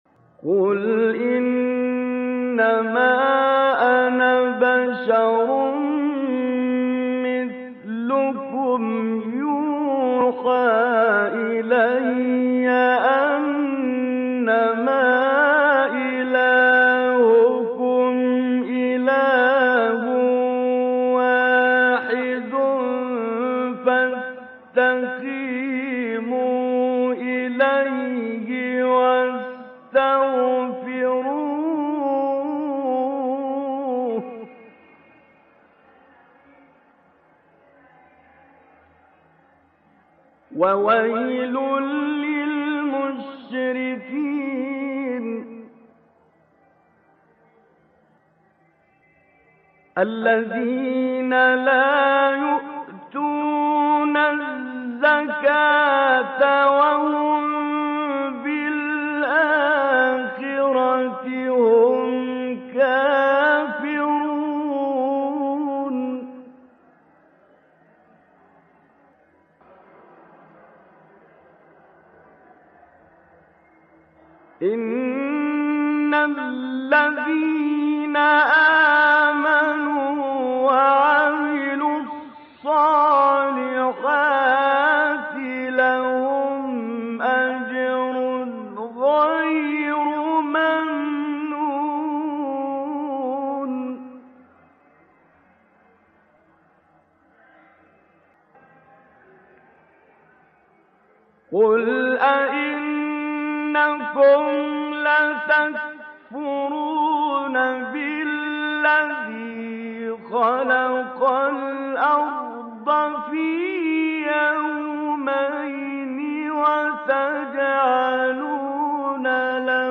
مقطعی از سوره فصلت با صدای طنطاوی | نغمات قرآن
سوره : فصلت آیه: 5-12 استاد : محمد عبدالوهاب طنطاوی مقام : مرکب خوانی ( بیات* صبا * ترکیب عجم صبا) قبلی بعدی